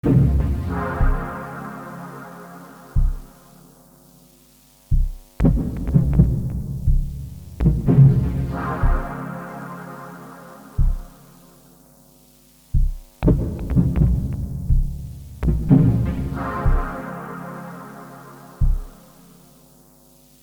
Digitone II
This is DNII (as usual, straight from the stereo-out).
• a chunky dub chord. I’ve used FM, creating lots of overtones … but strongly filtered with resonance for the “chunk”
• a supersaw chord, first plucky, then ringing. Later, together with the bass, plucky again.
• a noise floor with 50 Hz hum (FM Drum machine)
• later: a clicky bass sound, also done with FM
The chord is acutally not a minor chord (although it feels minor), the whole chord is: c# f g# [a#] c.
There are always many factors playing in, for example how strong a finger hit a key, I hear the f louder than the others, so I programmed a stronger velocity for that note.